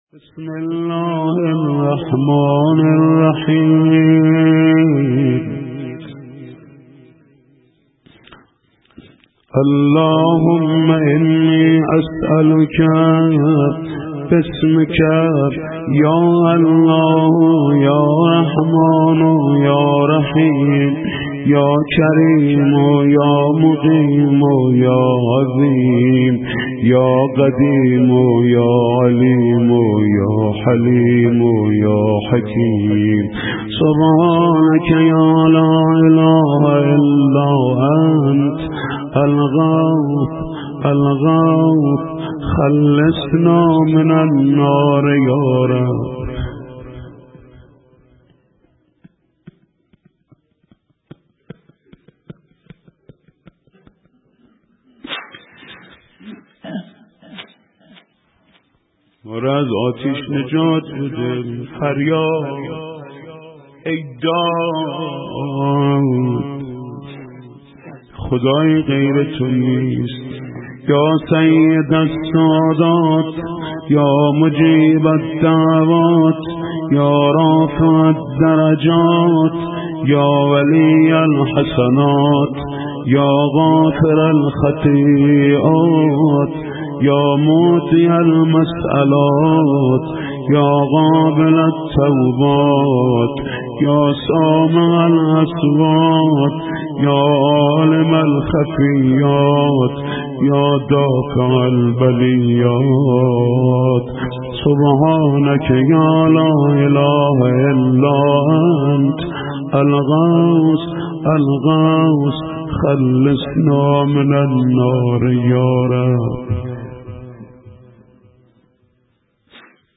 صوت/ دعای جوشن کبیر با صدای حاج محمود کریمی+ متن